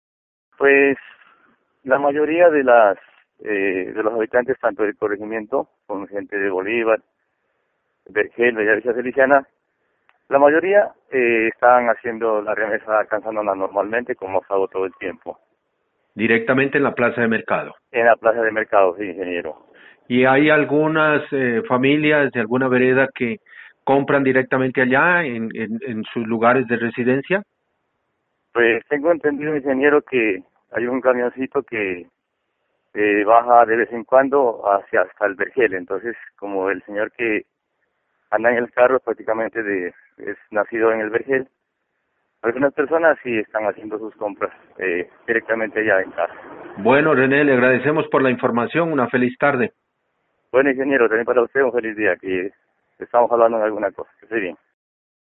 A los líderes comunitarios les hicimos la misma pregunta: ¿En la actualidad en donde se abastecen de alimentos las familias de su corregimiento?